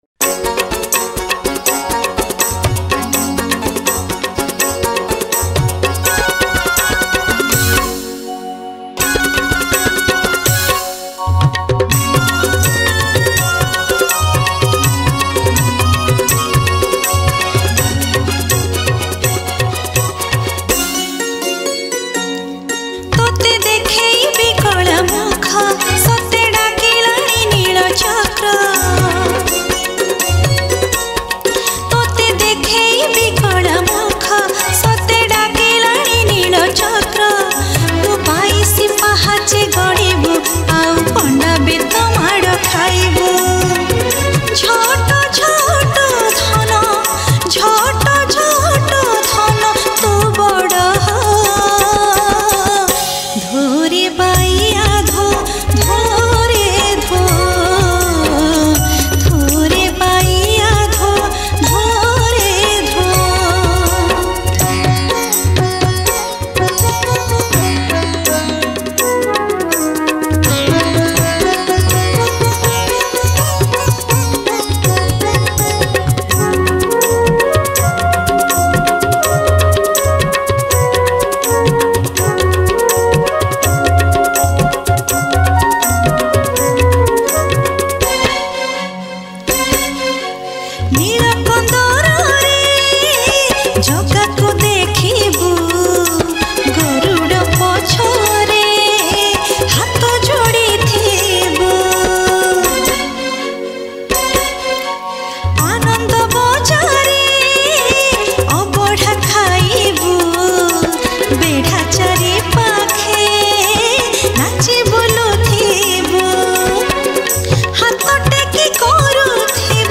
Odia New Bhajan Song